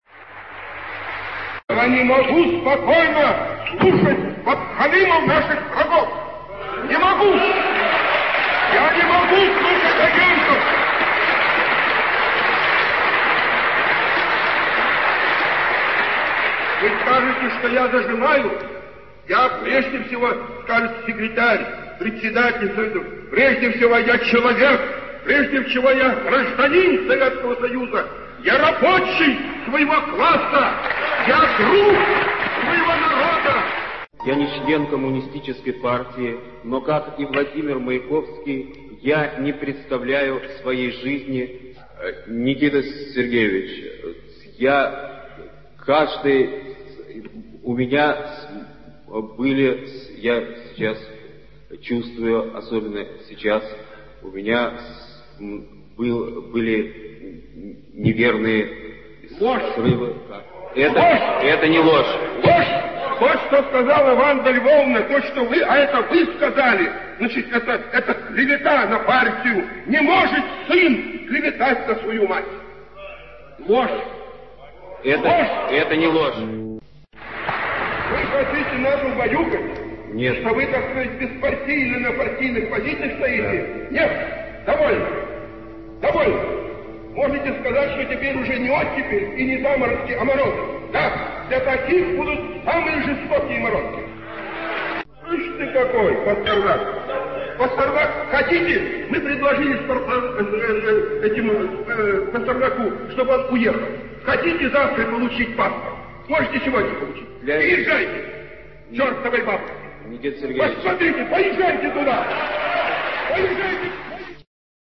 Сохранилась аудиозапись выступления Андрея Вознесенского на встрече партийного и государственного аппарата с интеллигенцией. Встреча та происходила 7 марта 1963 года. В довольно изящной форме Вознесенский попытался было выразить мысль, что хотя он, подобно Маяковскому, членом Коммунистической партии не является, но, как и Маяковский, он не представляет «своей жизни, своей поэзии и каждого своего слова без коммунизма». Уже первые слова его выступления, однако, вызвали у тогдашнего руководителя страны Н. С. Хрущёва самую настоящую ярость.
Сотни людей, собравшихся в Свердловском зале Кремля, встречали гневные слова Никиты Хрущёва бурными и зачастую продолжительными — на несколько минут — аплодисментами.